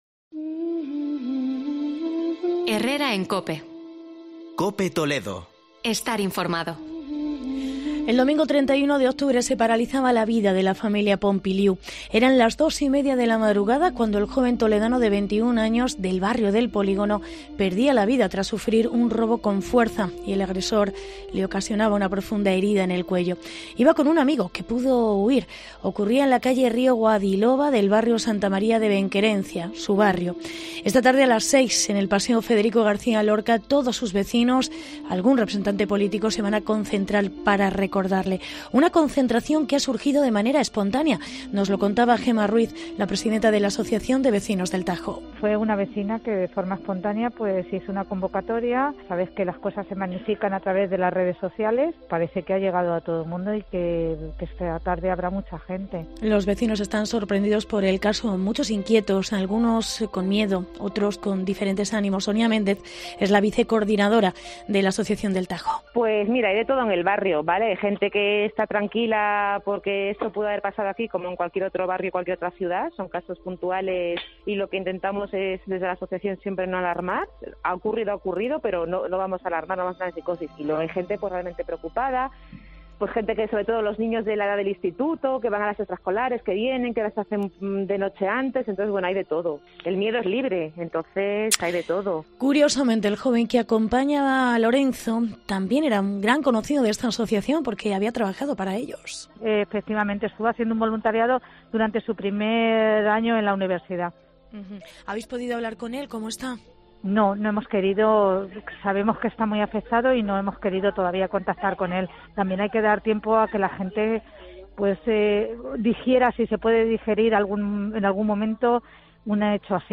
Entrevista AAVV El Tajo